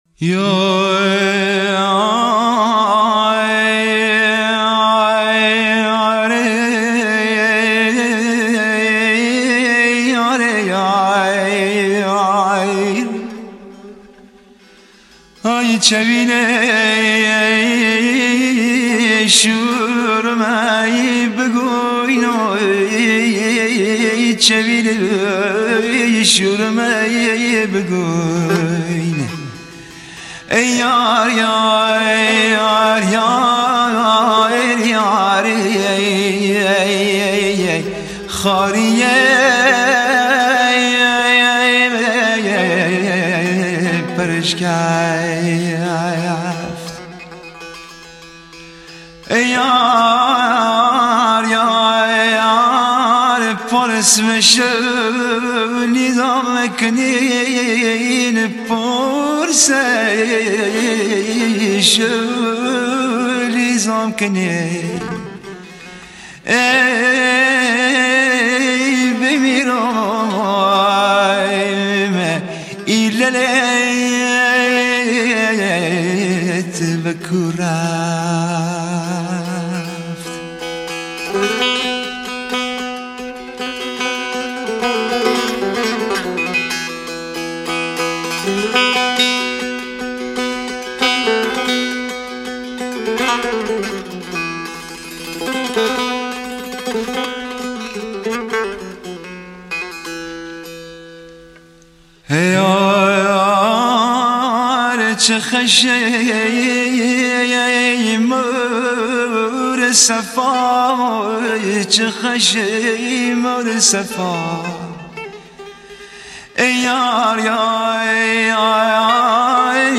Lori song
آهنگ غمگین بختیاری
آهنگ غمگین لری